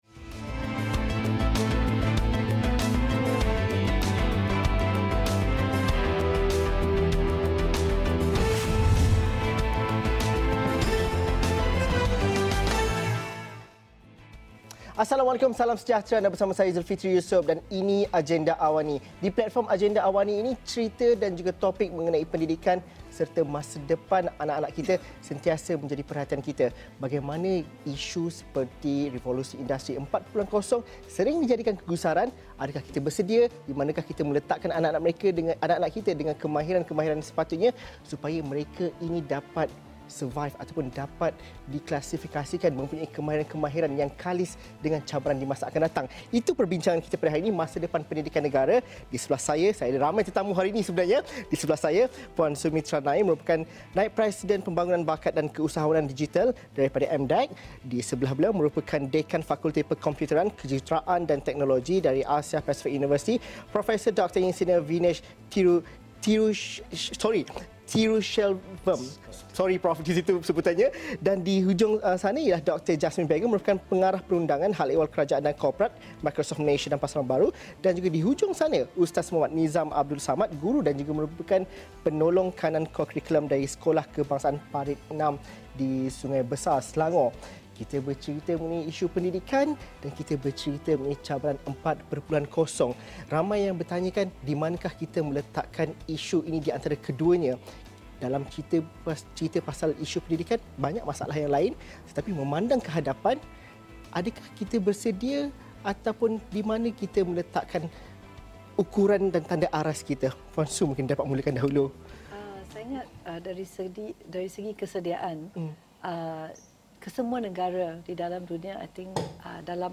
Diskusi penuh mengenai isu ini dalam Agenda AWANI.